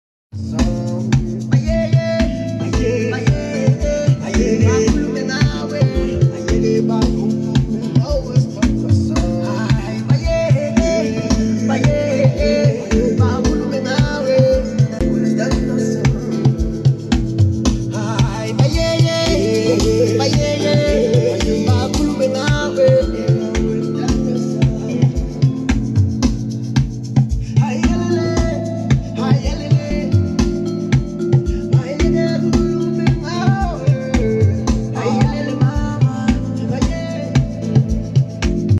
Check out the snippet of the song below.